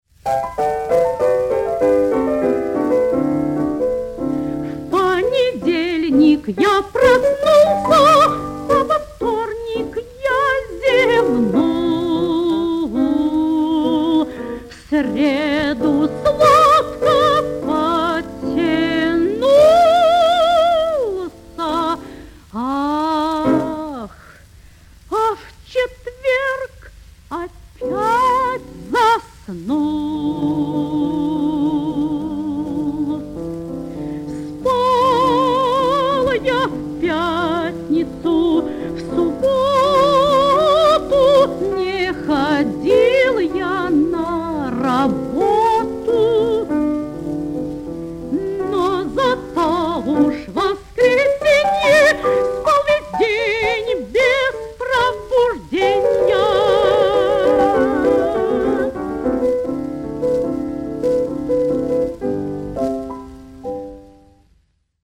в сопр. ф-но Исполнение 1954г. Матрица 24229 (патефонная)